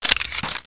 assets/ctr/nzportable/nzp/sounds/weapons/ppsh/magout.wav at af6a1cec16f054ad217f880900abdacf93c7e011